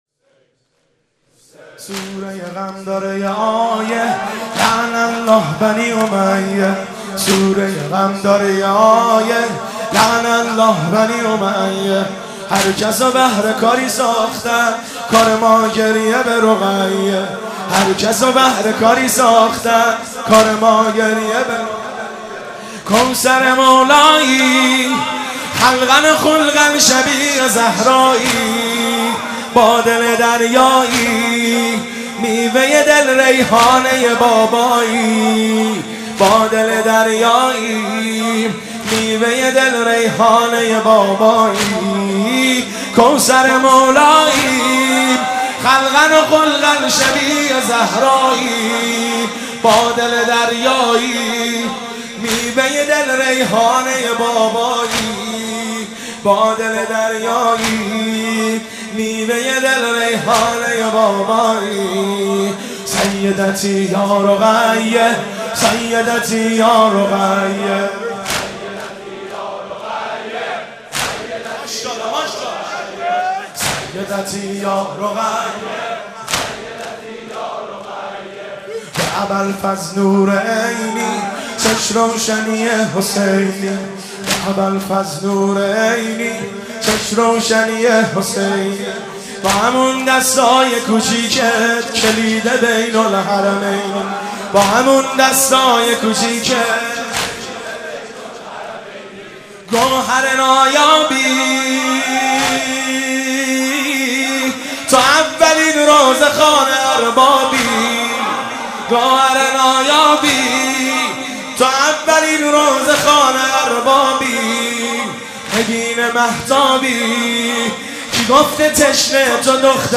مداحی سوره غم داره یه آیه(واحد)
شام غریبان محرم 1392
هیئت خادم الرضا(ع) قم